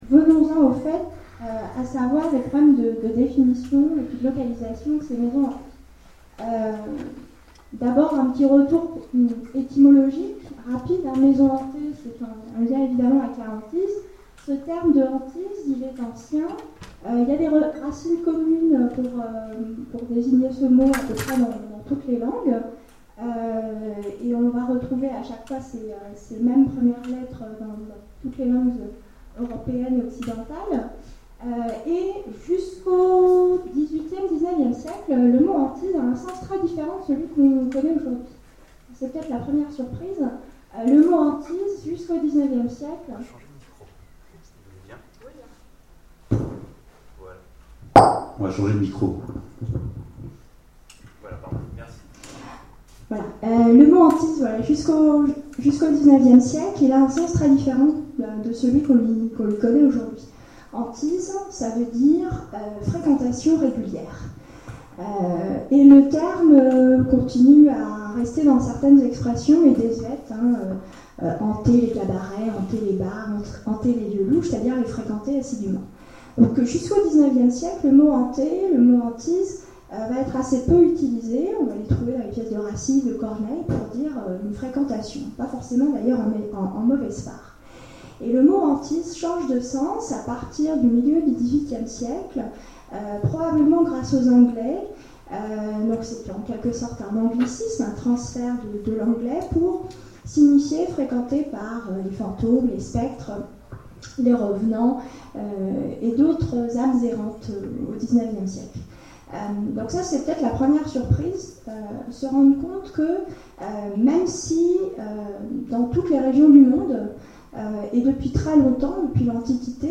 Bloody Week end 2013 : Conférence sur les Maisons Hantés
Conférence